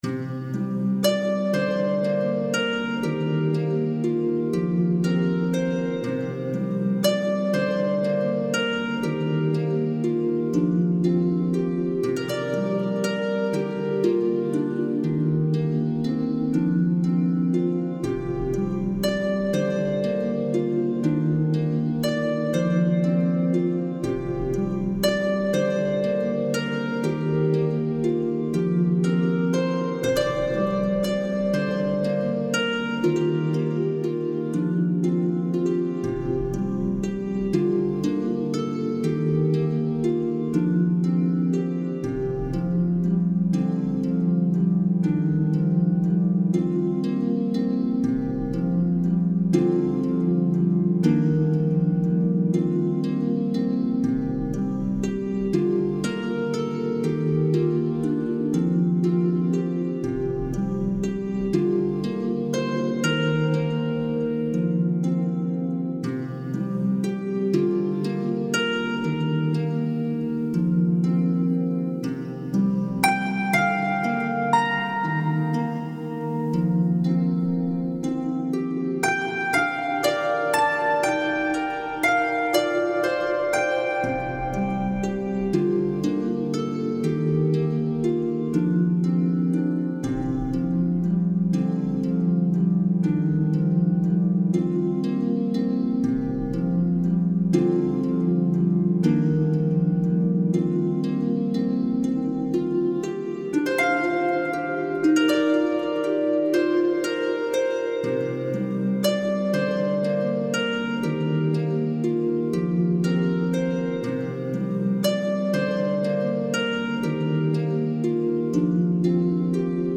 is for solo lever or pedal harp.